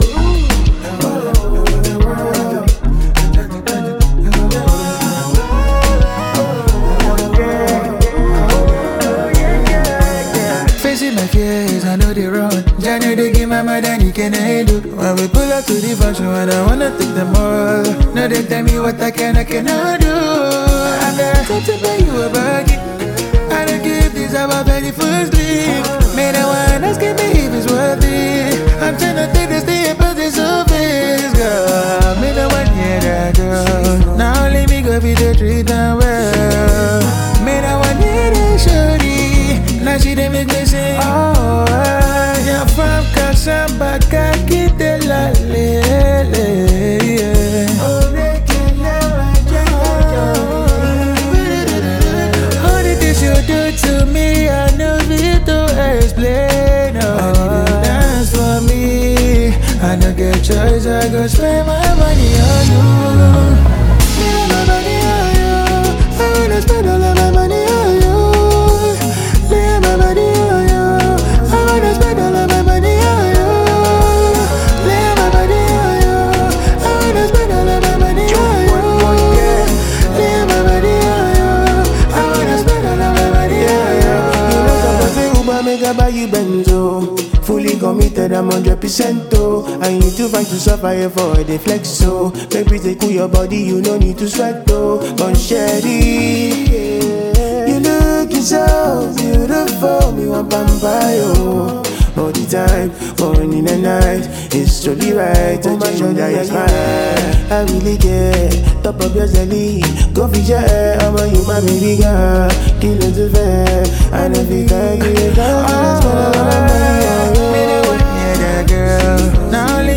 His smooth vocals carry the track with confidence and style.